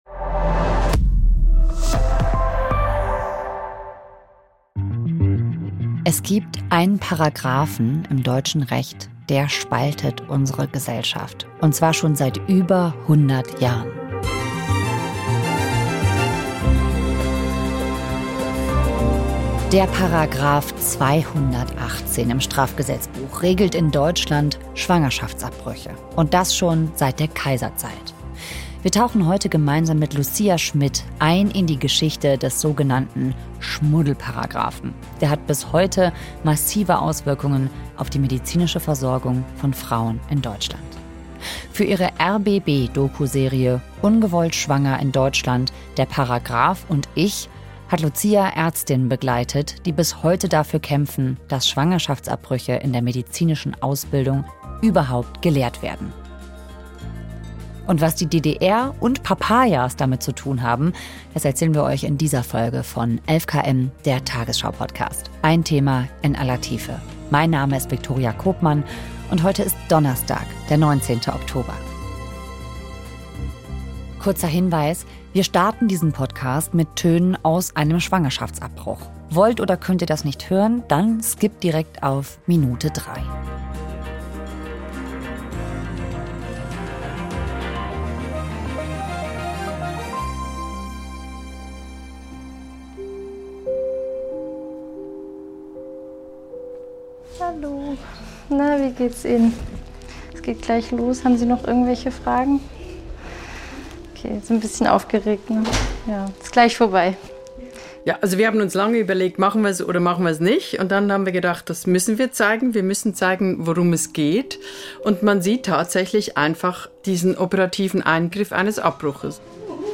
Denn auch die Ampelregierung konnte sich, trotz des Vorstoßes von Familienministerin Lisa Paus, bisher nicht darauf einigen, die Regelung zu kippen. Hinweis: Wir starten diese Podcastfolge mit Tönen aus einem Schwangerschaftsabbruch.